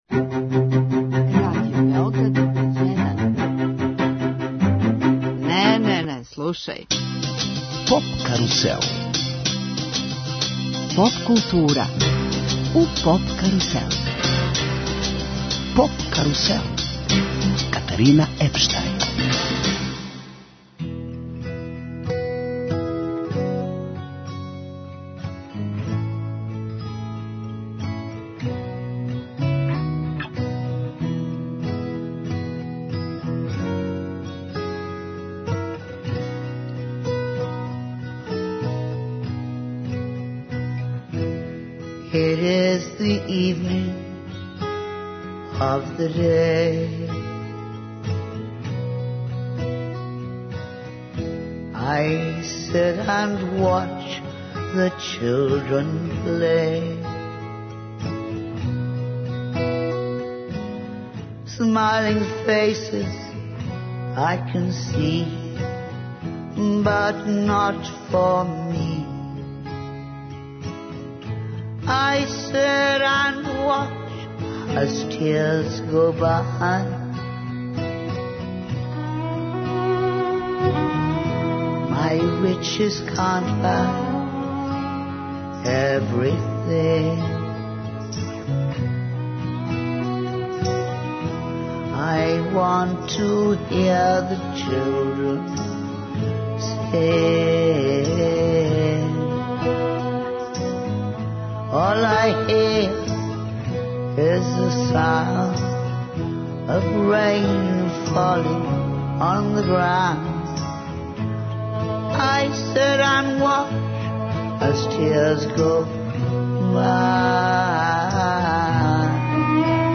Ексклузивно емитујемо интервју са Павоом Латјоненом, чланом састава 'Апокалиптика', који ће гостовати у Београду, у оквиру 'Ноћи музике'.